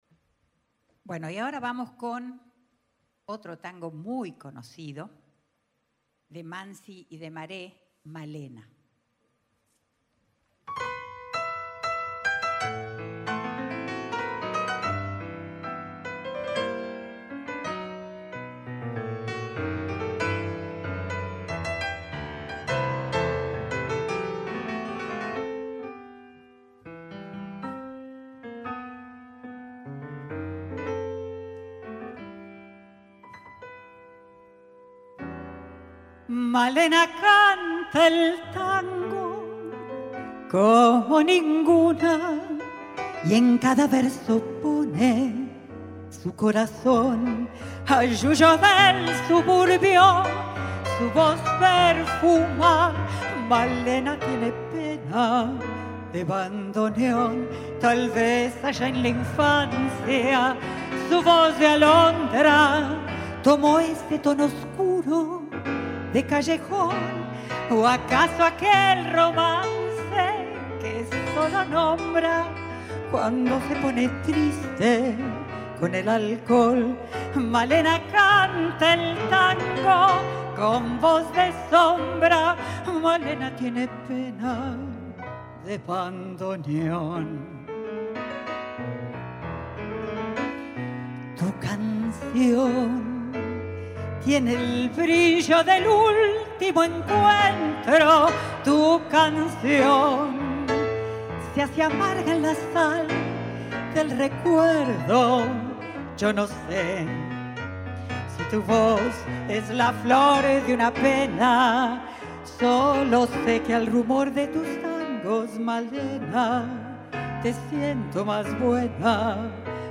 Temporada de Música de Cámara 2026.
Voz
Piano
Grabación realizada por el equipo de exteriores de las Radios Públicas el 9 de abril de 2026 en el Auditorio Vaz Ferreira.